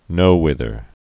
(nōwĭthər, -hwĭth-)